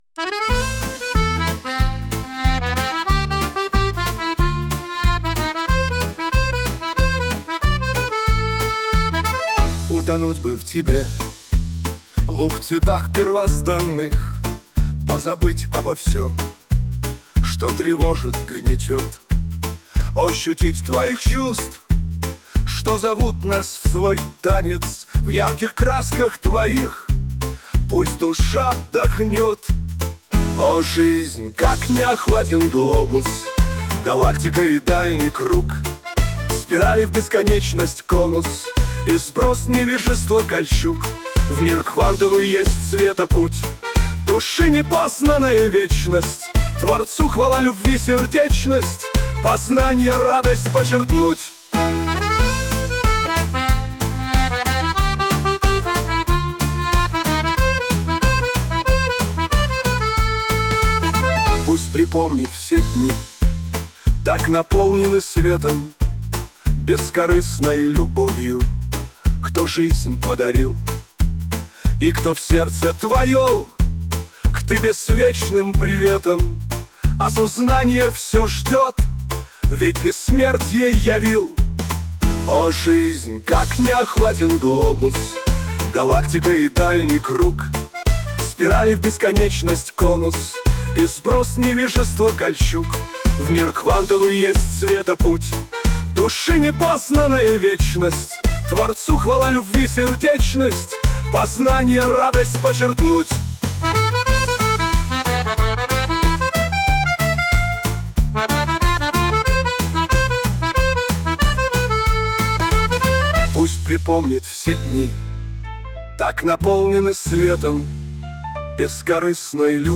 Мелодия на слова песни:
СТИЛЬОВІ ЖАНРИ: Ліричний